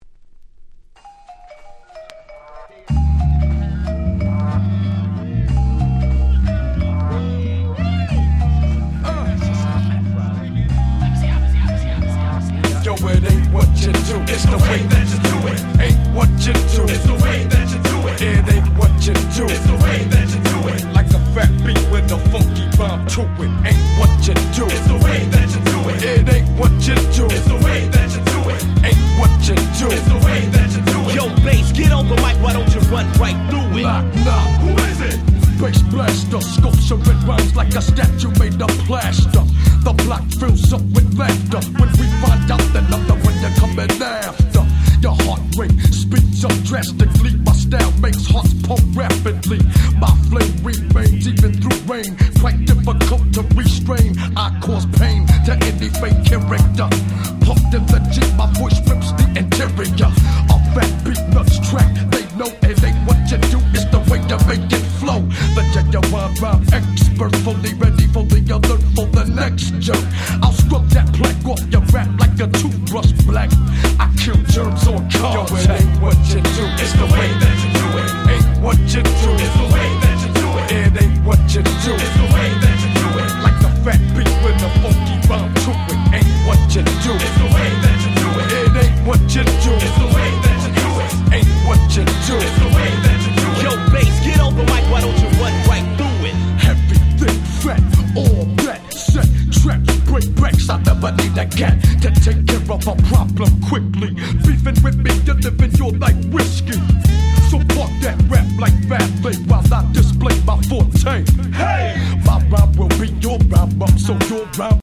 94' Super Nice Hip Hop !!
Underground Boom Bap